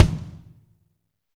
Index of /90_sSampleCDs/AMG - Now CD-ROM (Roland)/DRM_NOW! Drums/NOW_K.L.B. Kit 1
KIK KLB K01R.wav